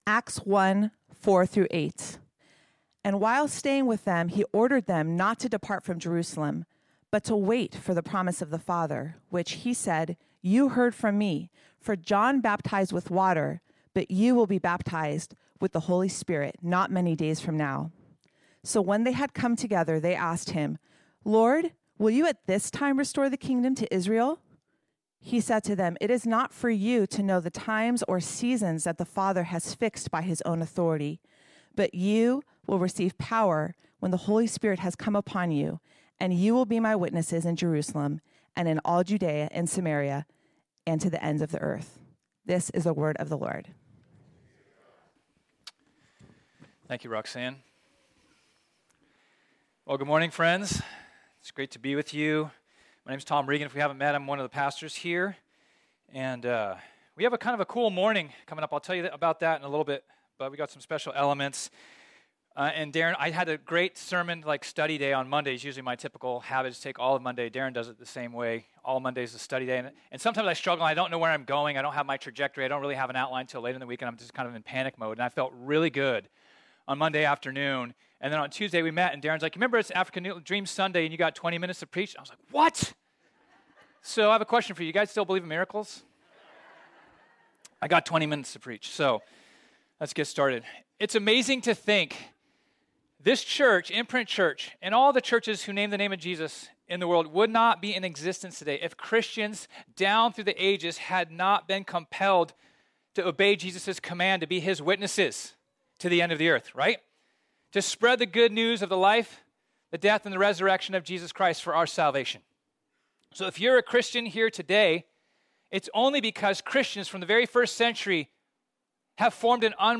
This sermon was originally preached on Sunday, February 20, 2022.